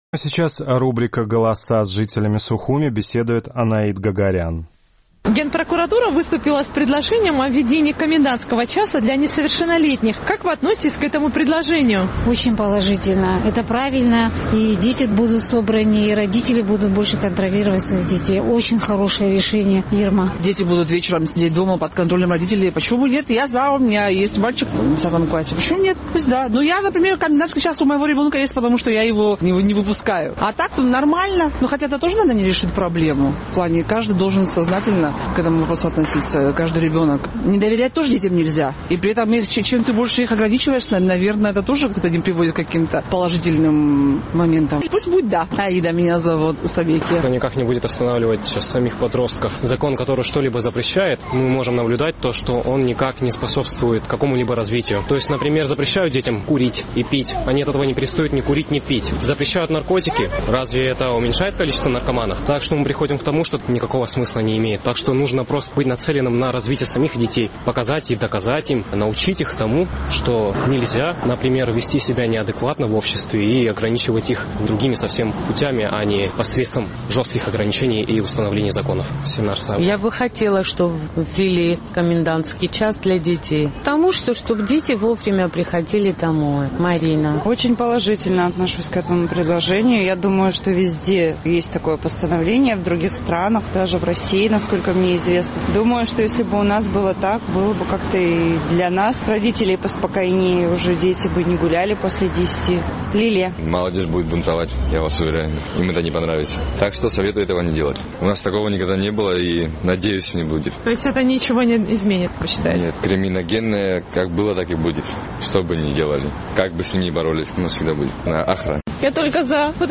Генпрокуратура Абхазии выступила с предложением о введении комендантского часа для несовершеннолетних. Наш сухумский корреспондент поинтересовалась у местных жителей, как они относятся к этой инициативе.